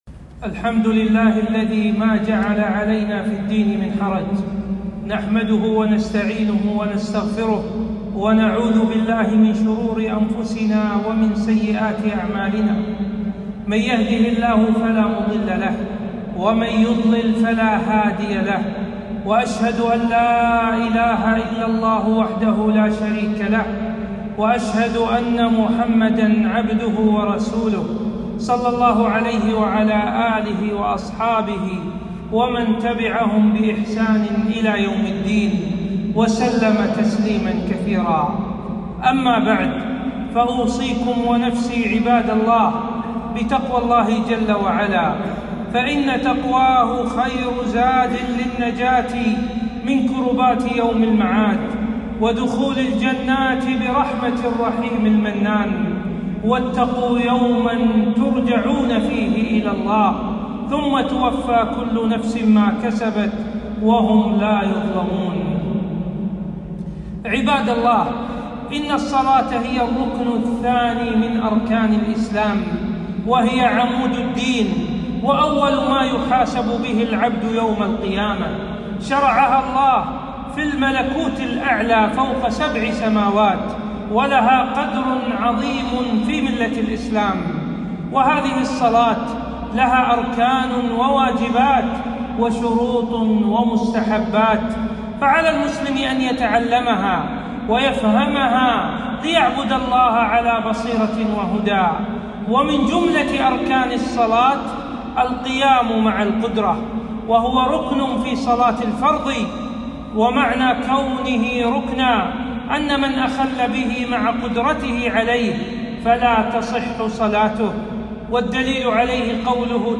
خطبة - أحكام الصلاة على الكرسي